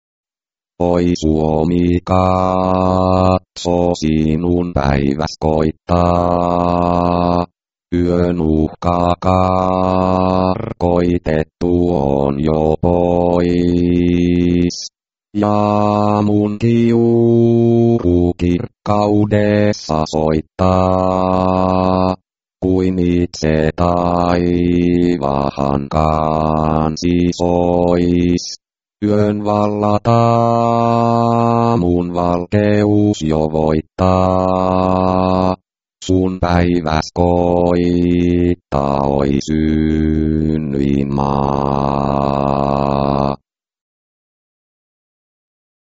95. Singing voice: "Finlandia" 0.43